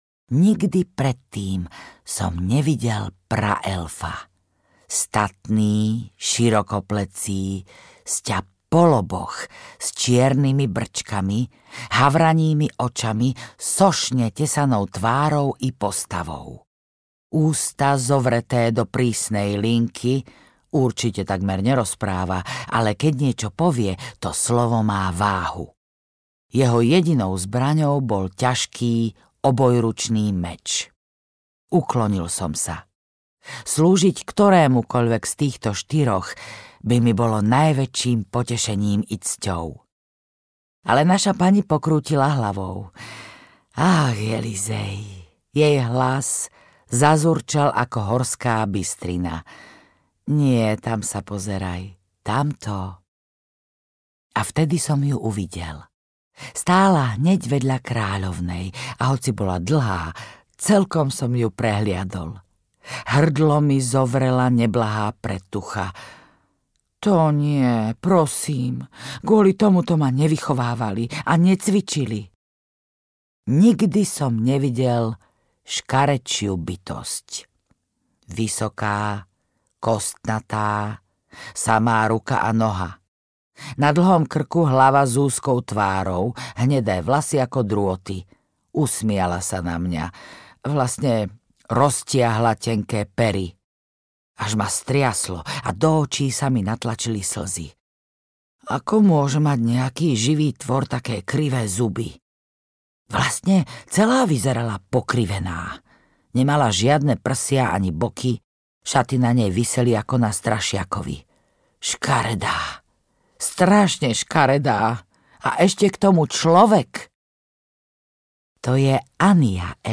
Údolie ľalií audiokniha
Ukázka z knihy